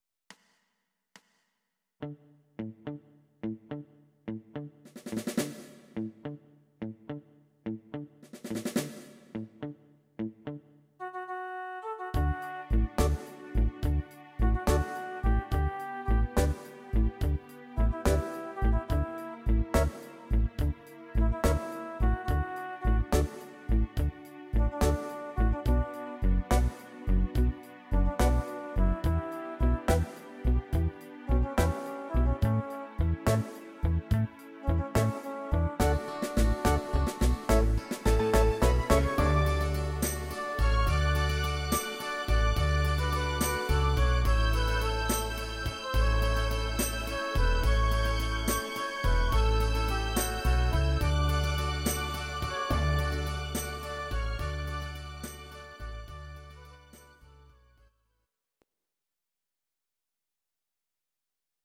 Audio Recordings based on Midi-files
Pop, Oldies, Ital/French/Span, 1960s